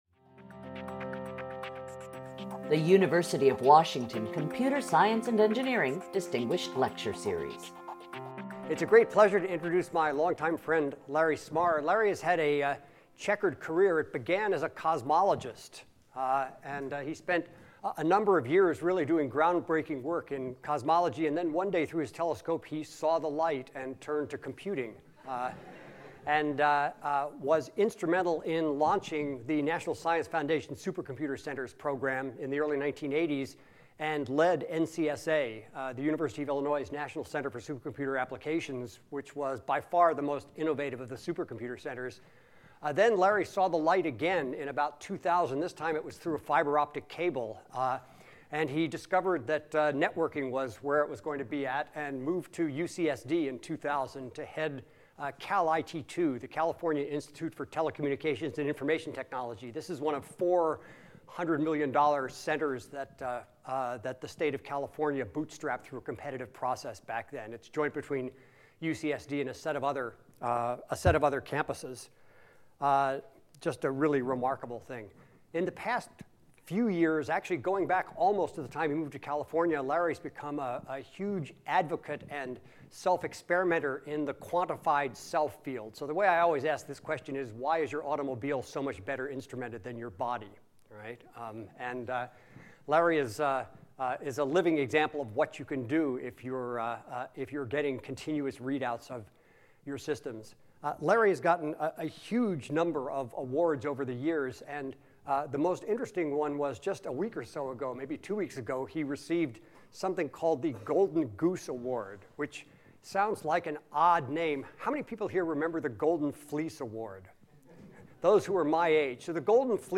CSE Distinguished Lecture Series